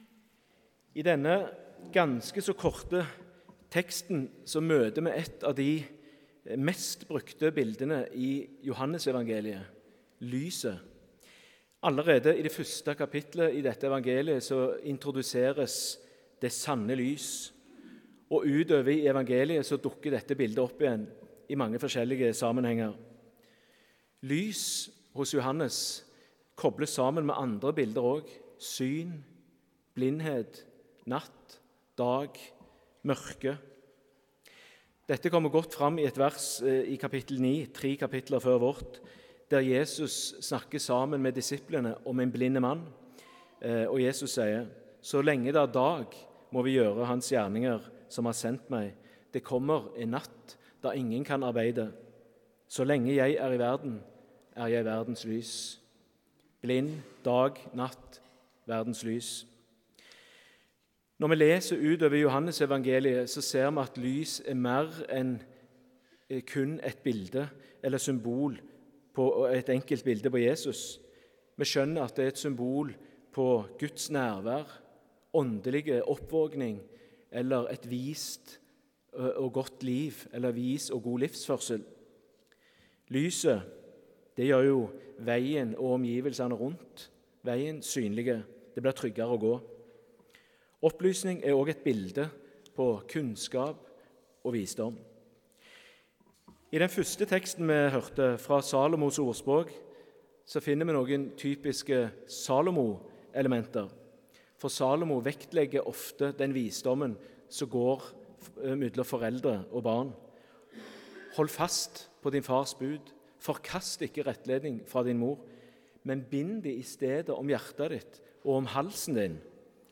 Utdrag fra talen